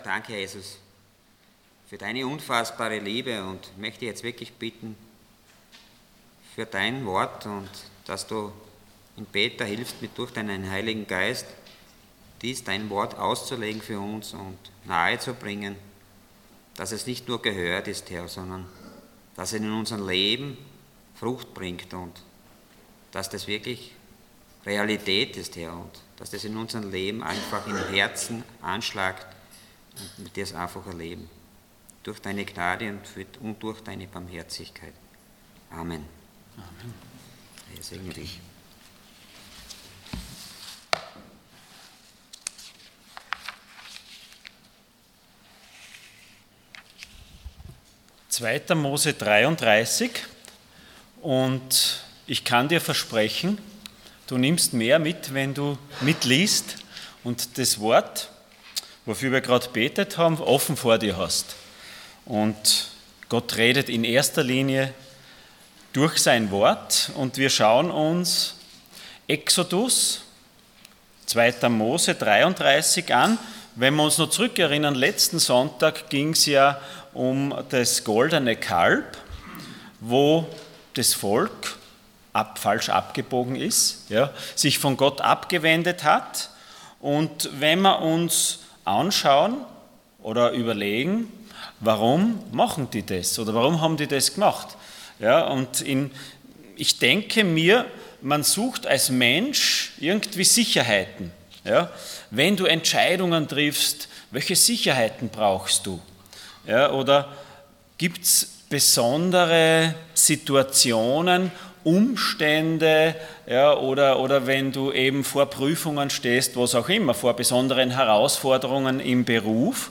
Passage: 2. Mose 33 Dienstart: Sonntag Morgen Moses Bitten und Gottes Zusagen Themen: Erkenntnis « Ein Super-GAU Nach dem Super-GAU, kann es weitergehen?